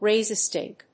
アクセントráise [creáte, kíck ùp] a stínk